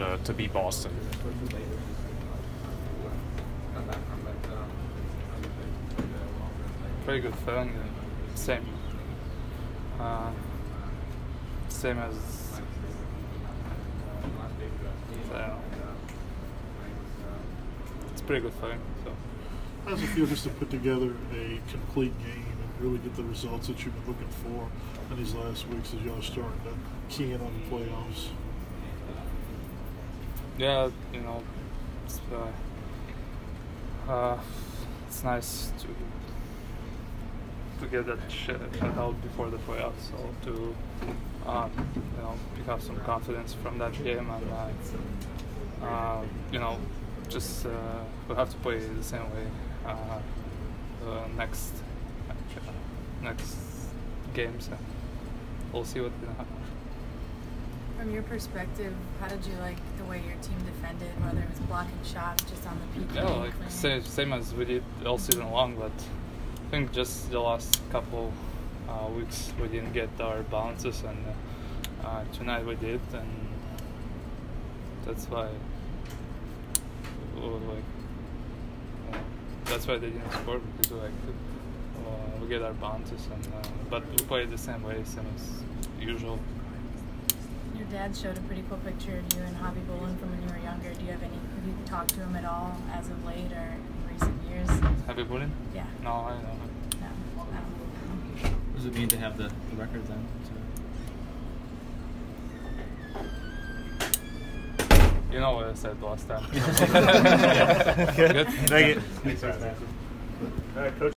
Andrei Vasilevskiy post-game 4/3